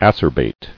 [ac·er·bate]